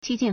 激进 (激進) jījìn
ji1jin4.mp3